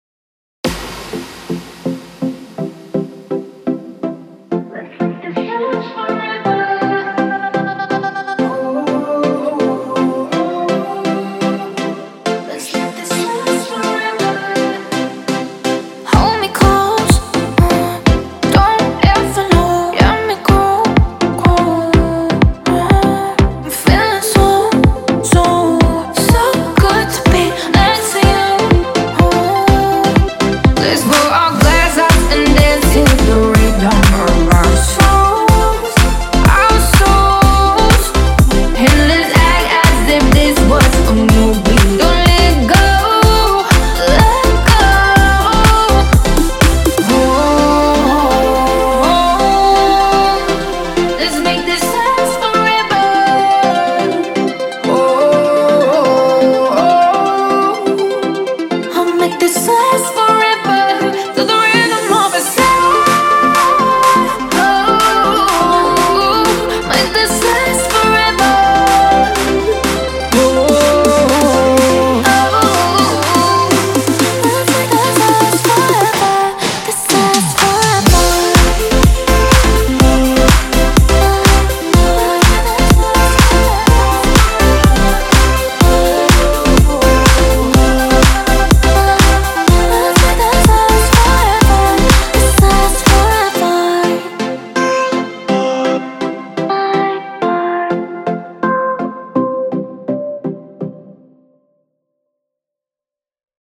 它包含来自7位专业歌手22种全无伴奏合唱，适用于各种音乐风格。